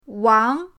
wang2.mp3